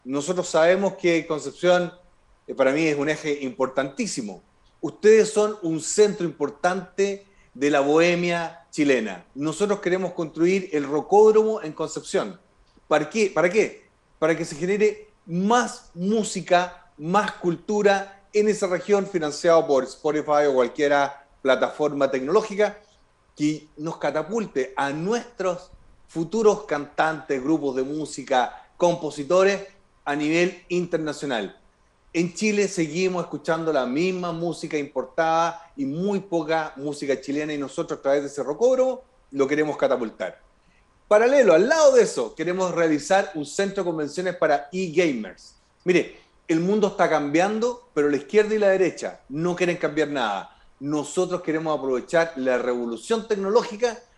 Con entrevista a Franco Parisi finalizó Presidenciales en Medios UdeC - Radio UdeC